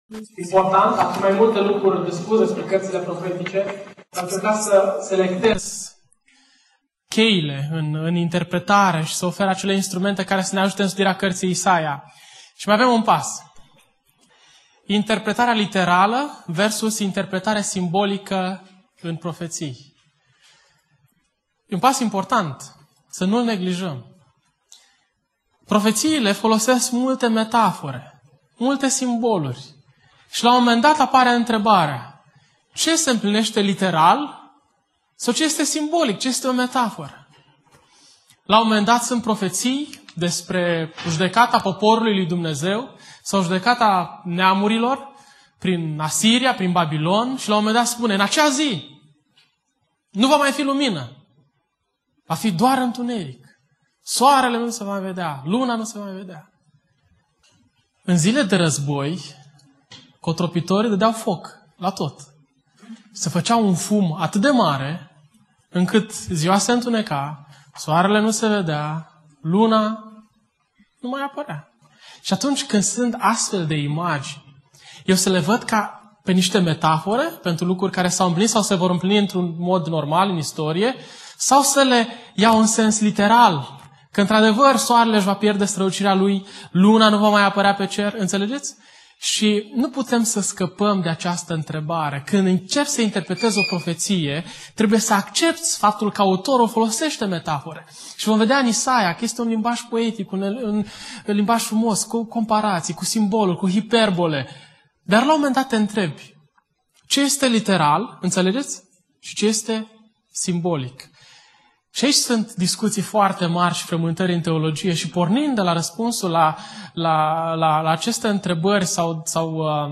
Conferinta Isaia-a 3a parte